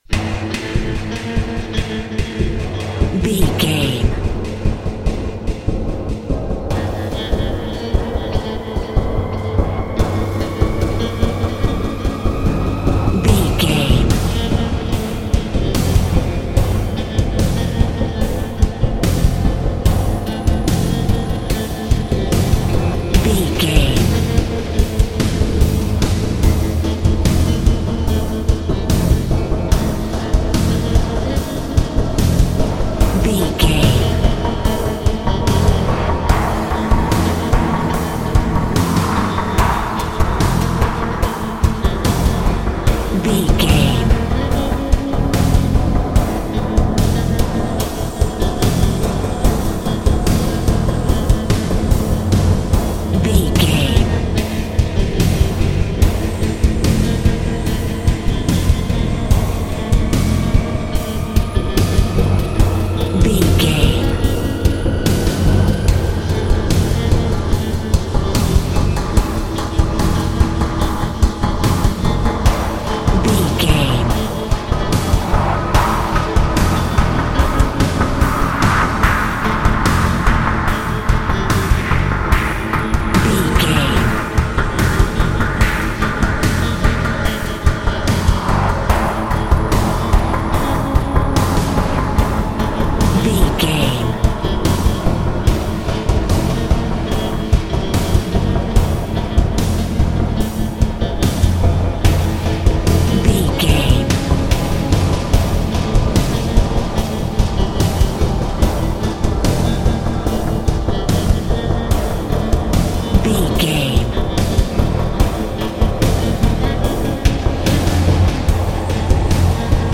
Thriller
Aeolian/Minor
synthesiser
drum machine
electric guitar